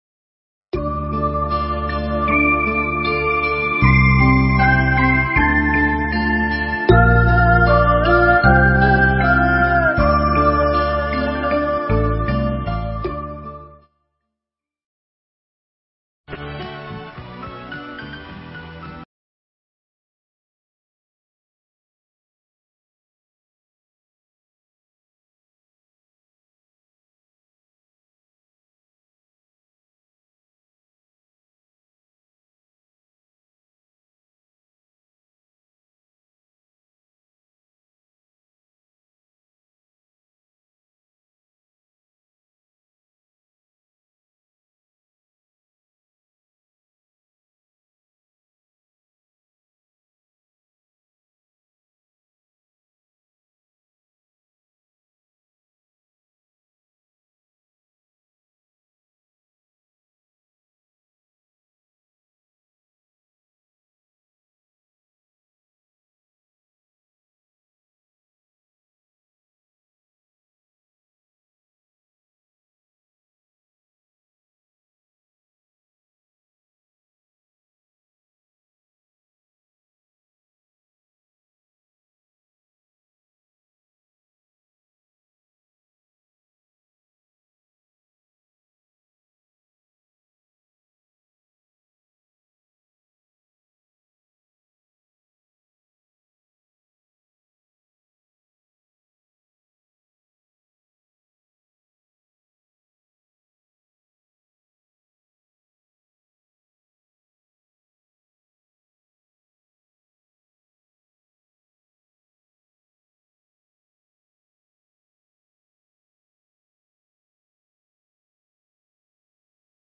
Mp3 Thuyết Giảng Tinh Thần Thiền Trúc Lâm Yên Tử – Hòa Thượng Thích Thanh Từ giảng tại Trúc Lâm Thiền Viện, Pháp, ngày 4 tháng 9 năm 1994